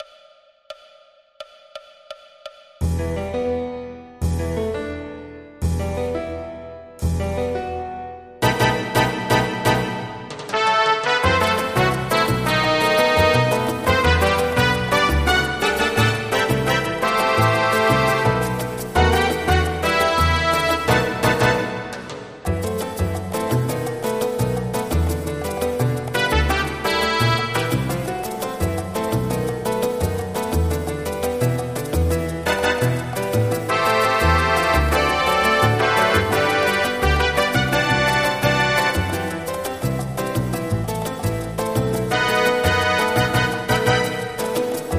midi/karaoke